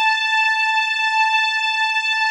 WAVE PAD.1.wav